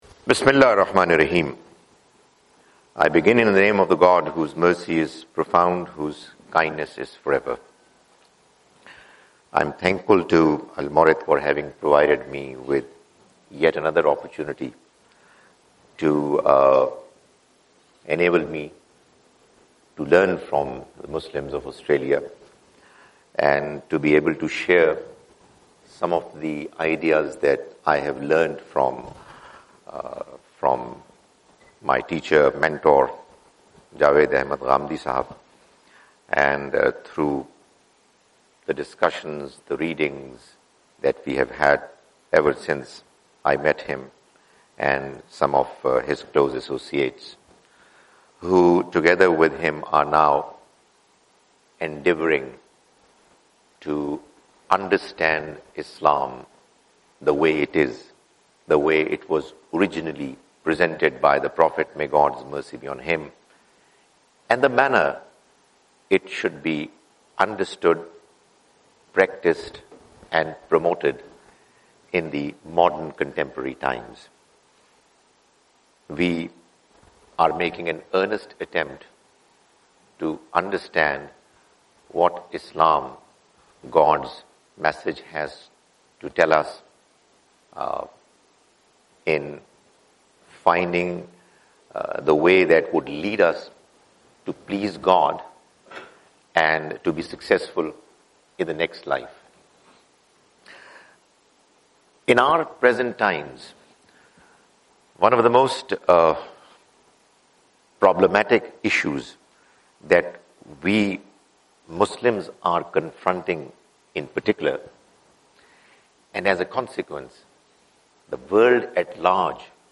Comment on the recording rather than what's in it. Australian Tour 2017: Sydney - Canberra - Melbourne